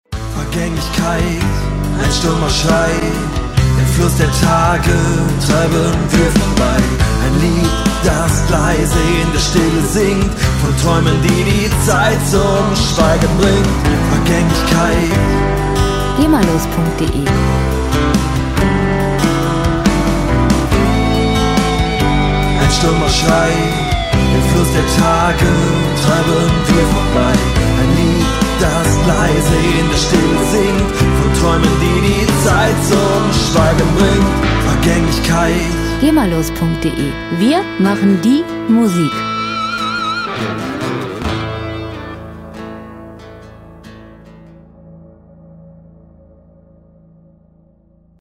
Pop Musik aus der Rubrik: "Popwelt Deutsch"
Musikstil: Indie Rock Ballad
Tempo: 140 bpm
Tonart: A-Moll
Charakter: tiefgründig, nachdenklich